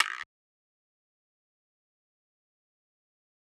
PBS (Plug Perc 1).wav